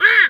duck_2_quack_04.wav